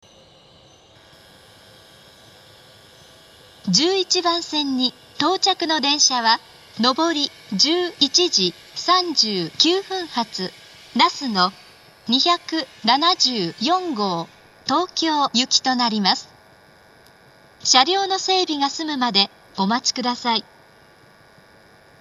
１１番線到着放送
koriyama11bansen-totyaku.mp3